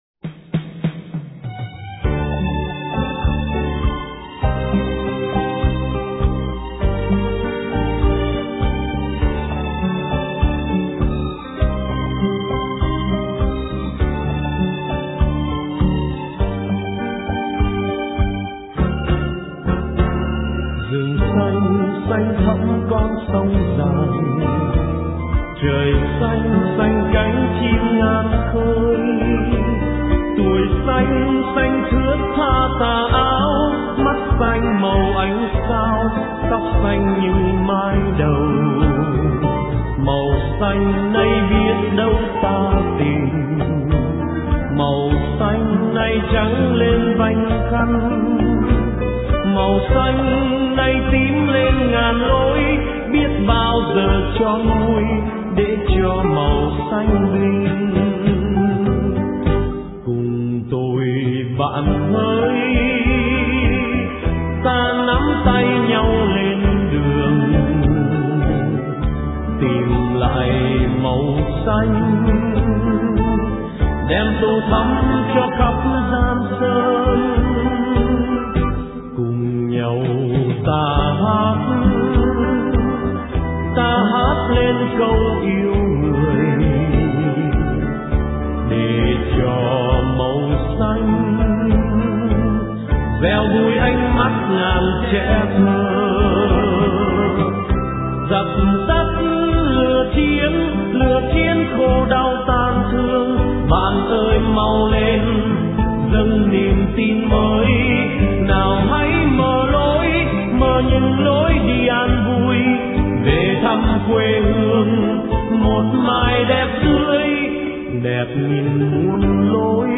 Dòng nhạc : Ngợi ca Thiên Chúa